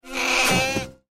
File:Baby ice borer roar.mp3
Baby_ice_borer_roar.mp3